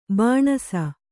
♪ bāṇasa